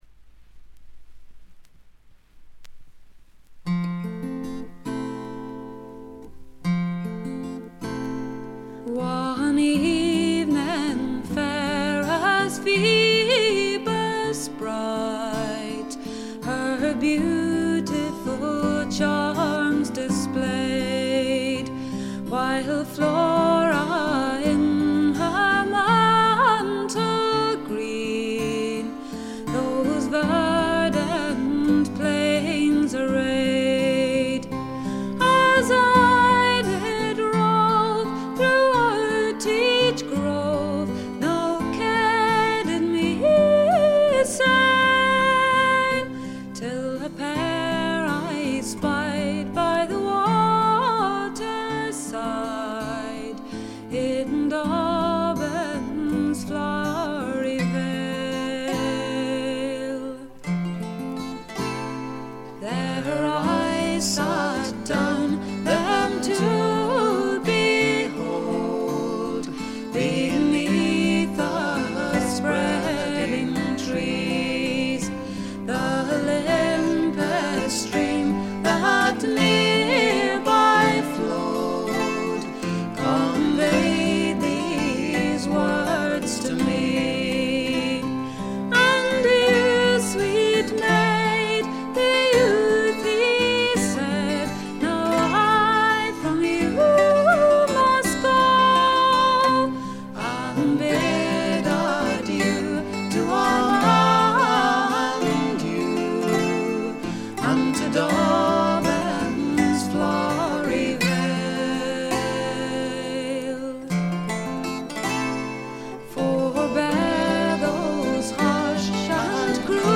アイルランドの女性シンガー・ソングライターでトラッドと自作が半々という構成。
天性のとても美しい声の持ち主であるとともに、歌唱力がまた素晴らしいので神々しいまでの世界を構築しています。
試聴曲は現品からの取り込み音源です。
Backing Vocals, Bouzouki
Harmonium [Indian]